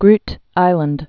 (grt īlənd)